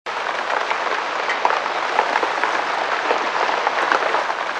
lluvia
lluvia.mp3